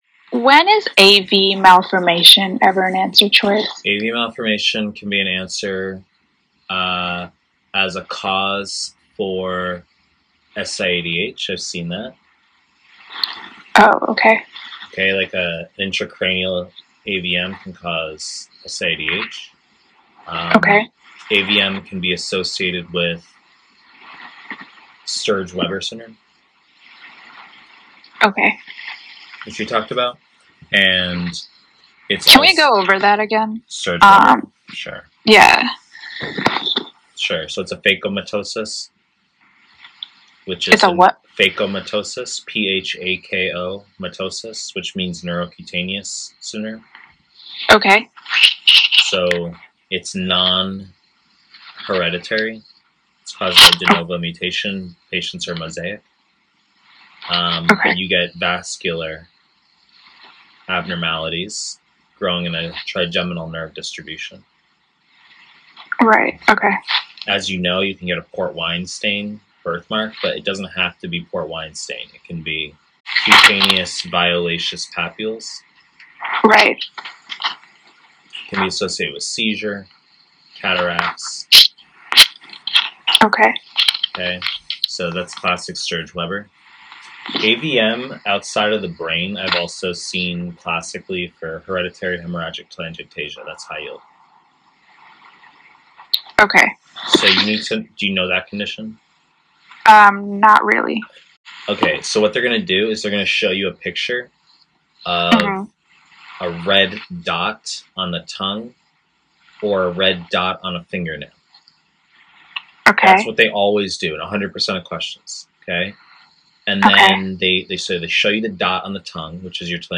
Pre-recorded lectures